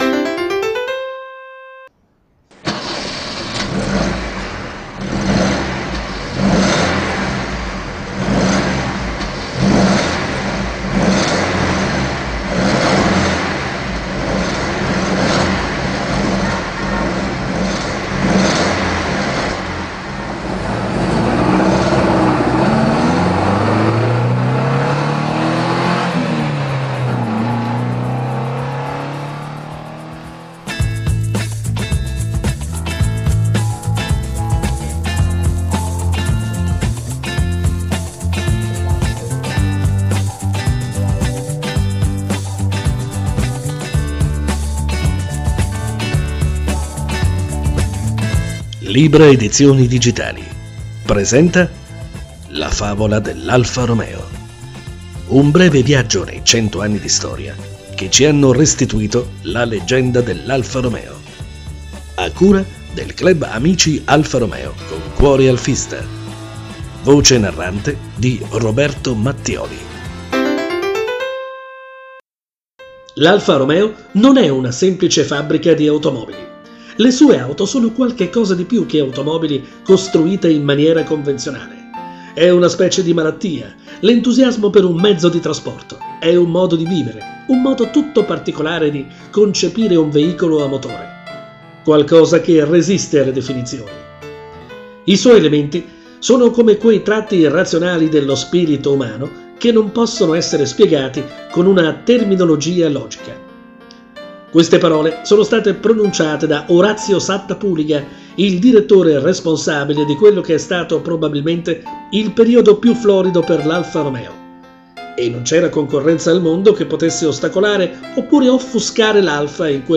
Formato: Audiobook (mp3)